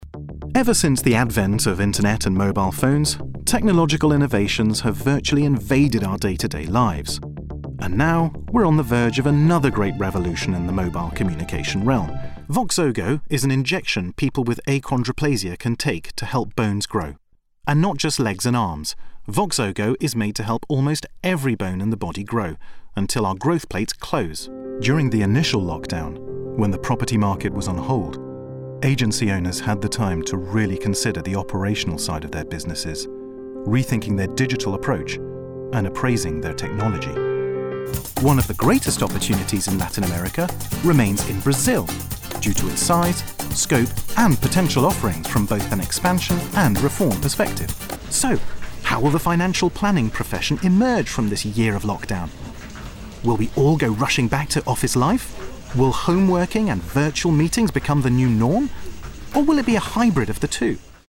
English (British)
Corporate Videos
Baritone
WarmFriendlyReliableAssuredEngaging